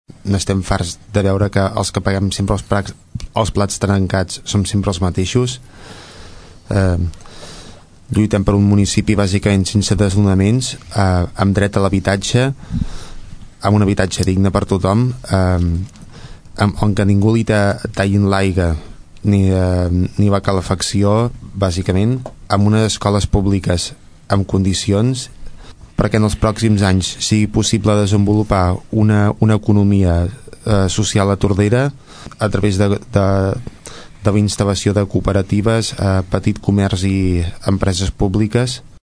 debat poble actiu
debat-poble-actiu.mp3